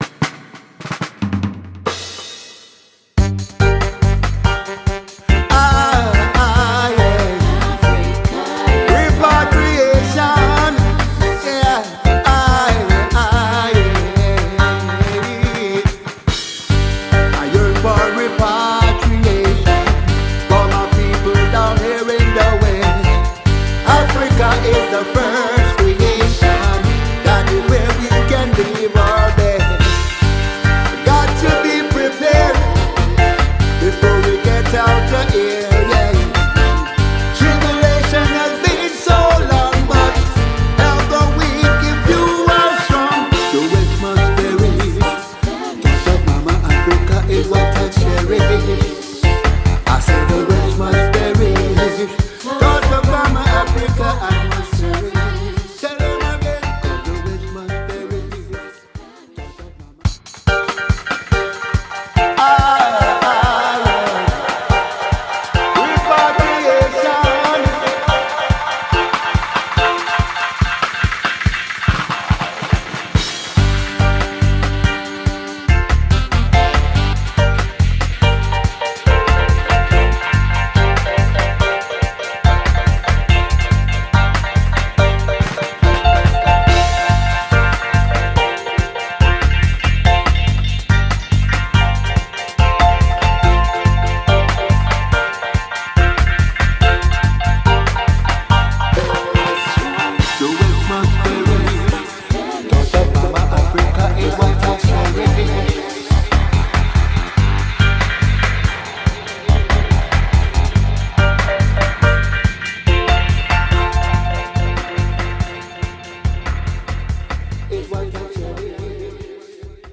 Voiced at Dou Weiss Studio, Toubab Dialaw, Senegal